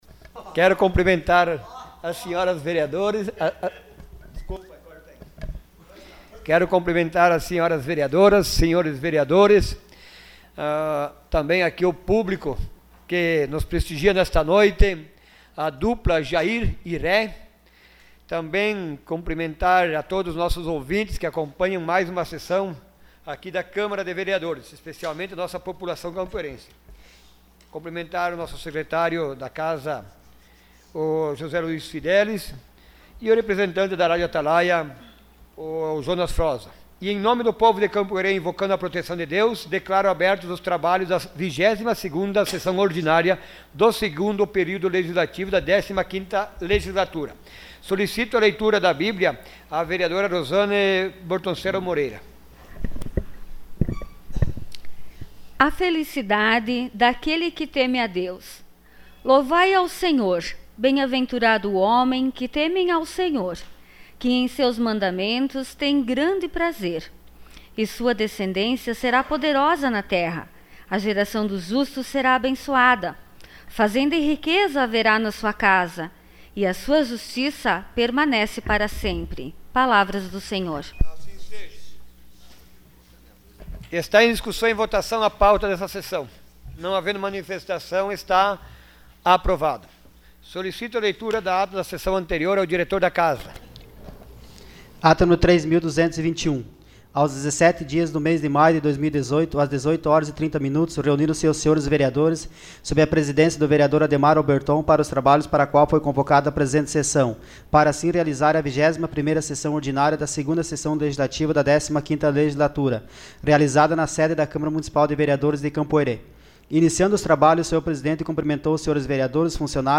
Sessão Ordinária dia 21 de maio de 2018.